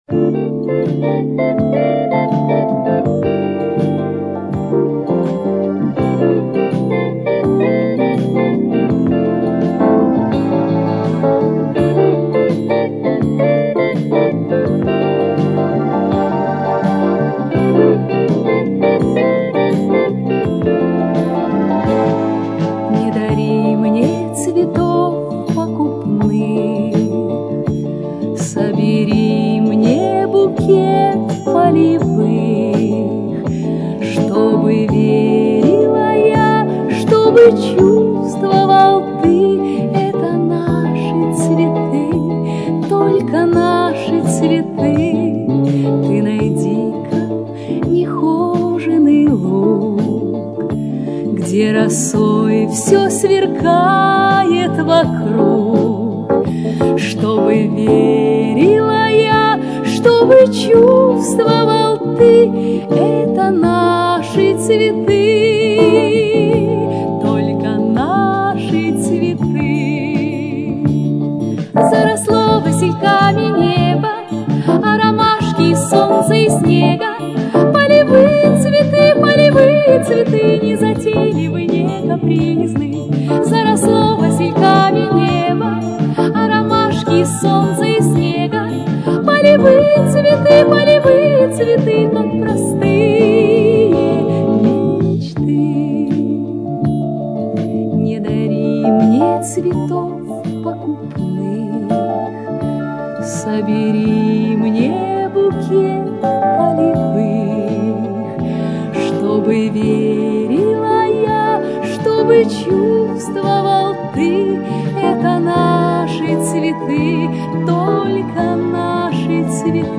вторая оцифровка кассетной записи